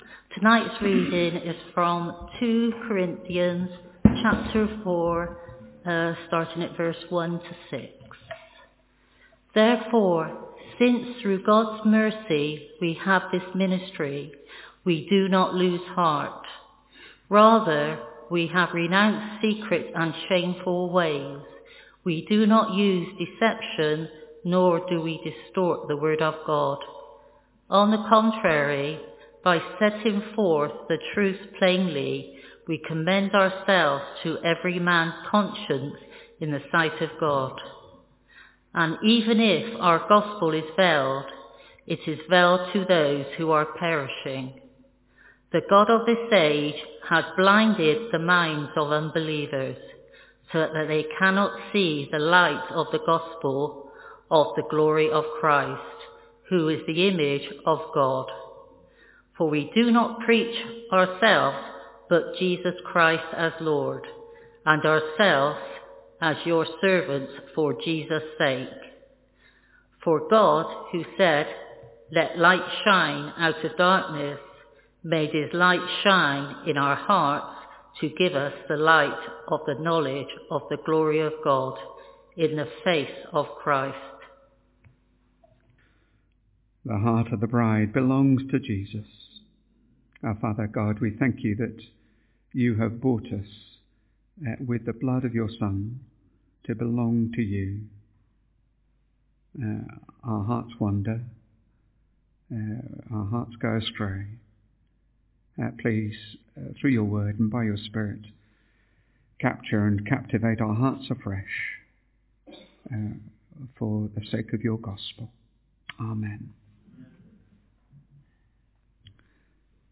Talk starts with prayer after reading at 1.25